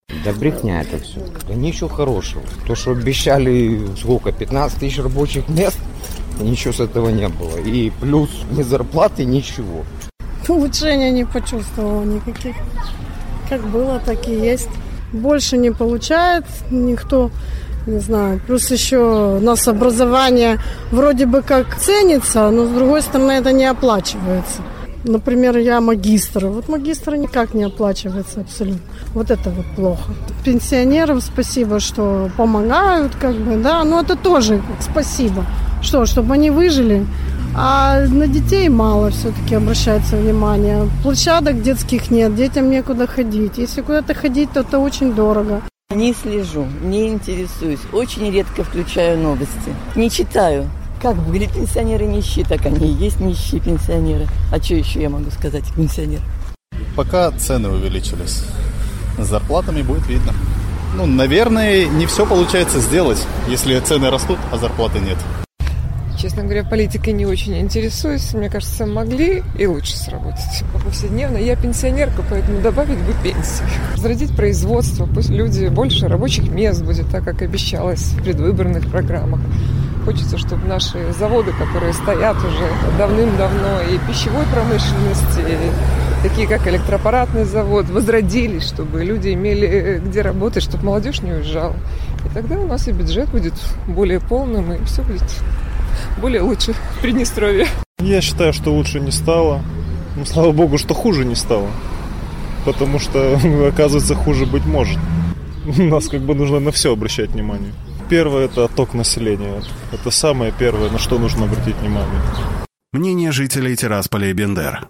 После оптимистичных заявлений руководства региона, наши корреспонденты спросили у прохожих на улицах приднестровских городов — ощущают ли они улучшения и довольны ли работой правительства региона: